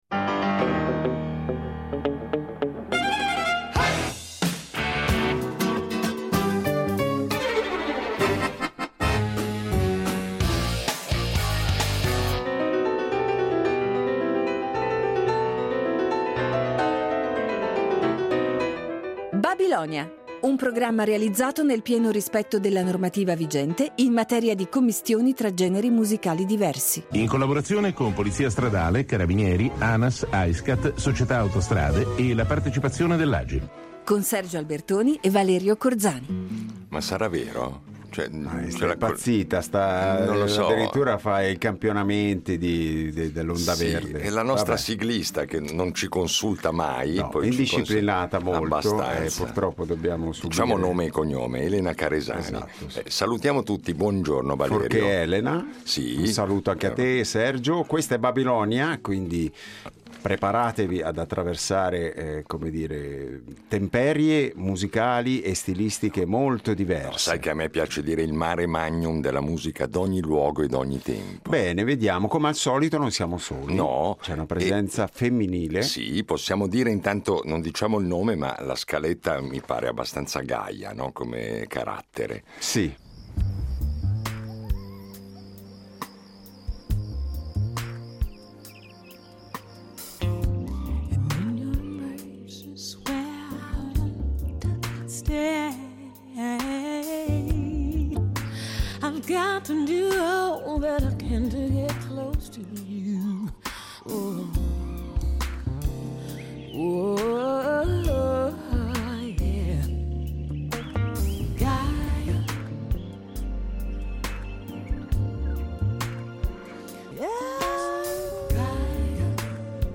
La nostra ospite di oggi è cantautrice, musicista e produttrice.
Il disco fonde elementi di elettronica, cantautorato e jazz, dando vita a un linguaggio evocativo e personale.